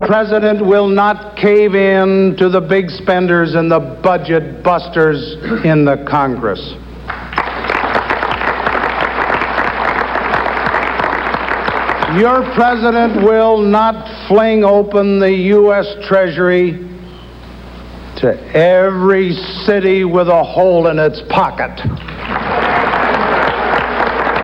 Gerald Ford tells a southern California Republican audience that he will not knuckle under and give handouts to the city of New York